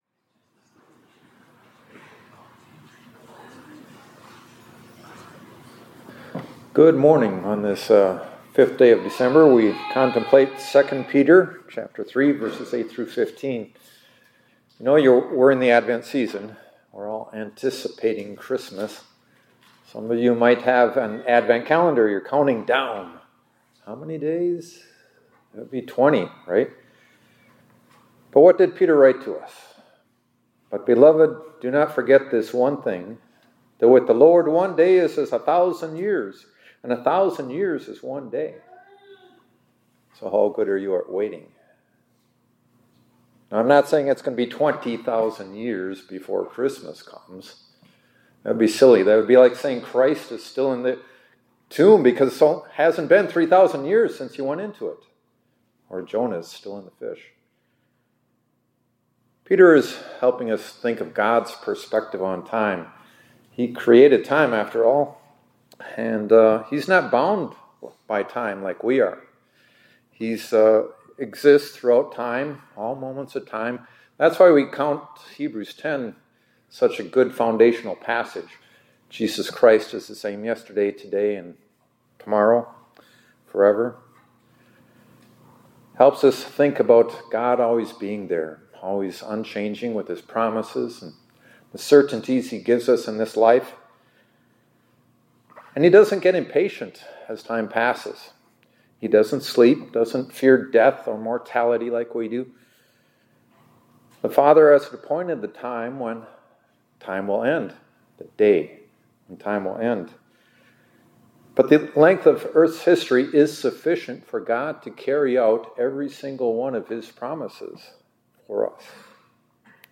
2024-12-05 ILC Chapel — God is Forebearing